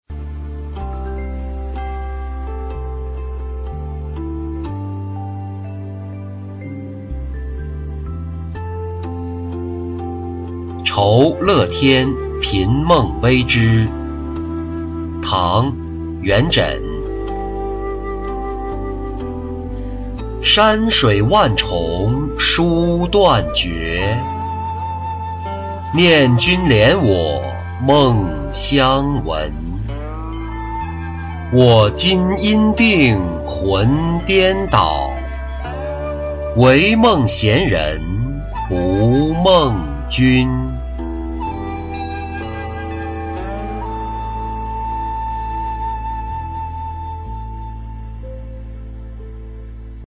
酬乐天频梦微之-音频朗读